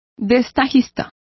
Complete with pronunciation of the translation of pieceworkers.